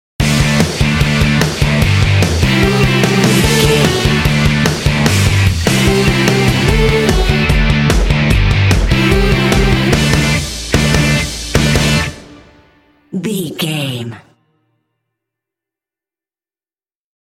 This cute indie track is ideal for action and sports games.
Uplifting
Ionian/Major
D
Fast
energetic
cheerful/happy
electric guitar
bass guitar
drums
vocals
classic rock
alternative rock